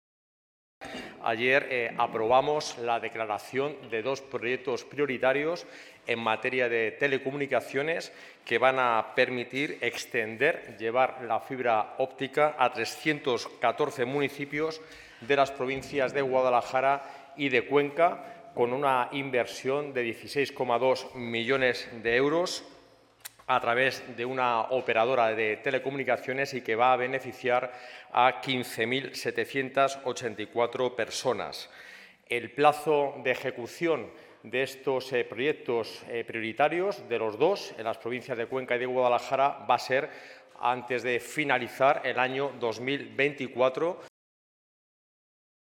escudero_-_declaracion_2_proyectos_prioritarios.mp3